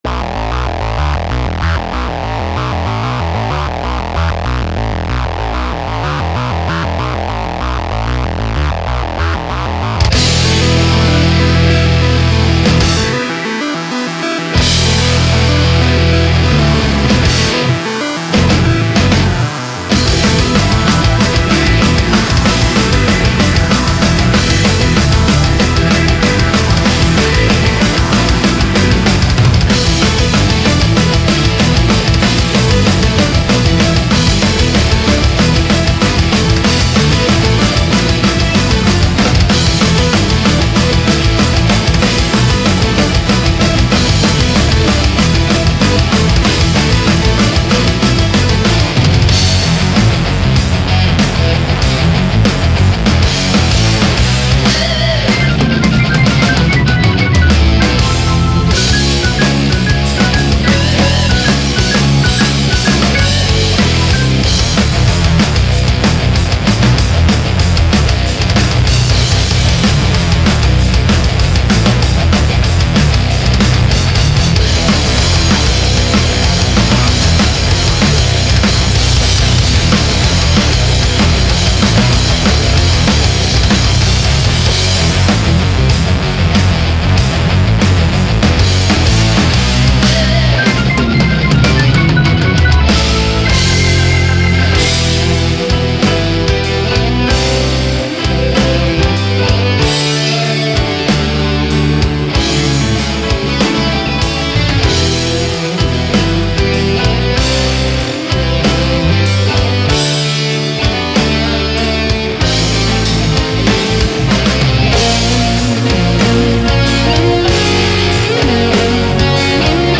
guitars
bass
keyboard
drums